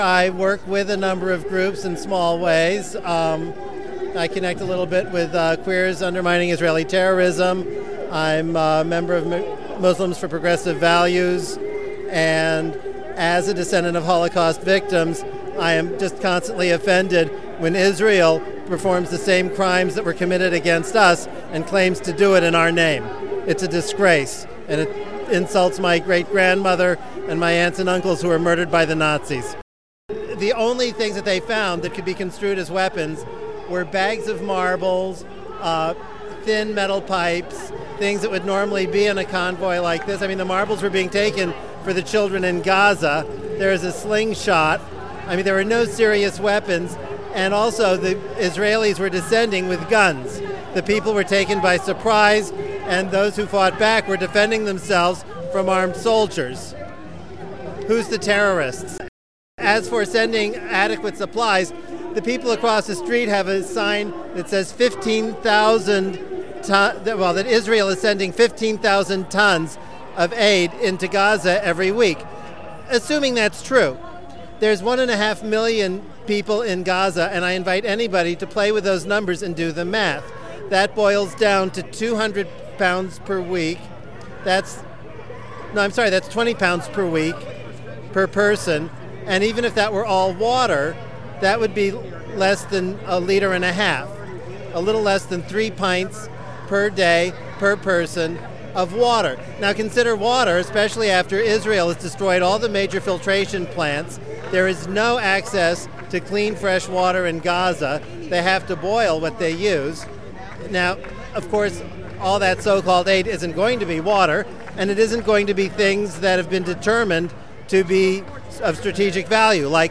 Two speech excerpts and three interviews from the 6/4/10 protest at the Israeli consulate